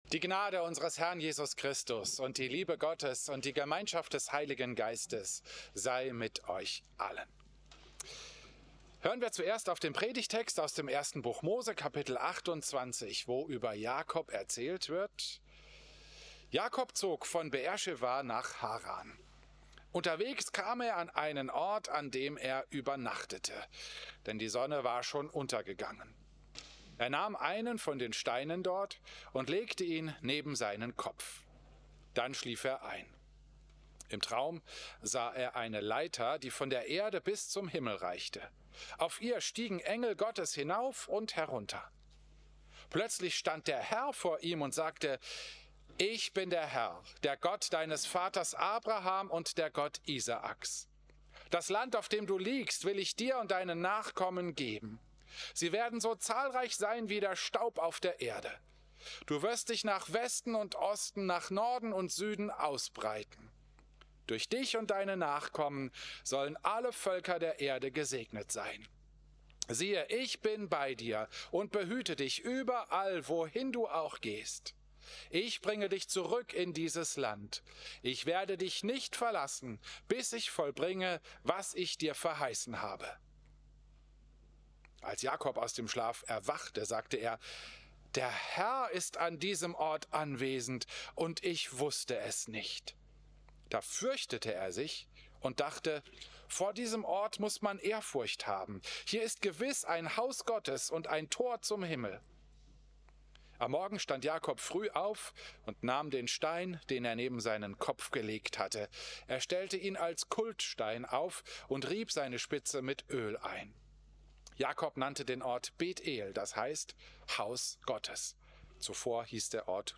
Predigt
Christus-Pavillon Volkenroda, 21. September 2025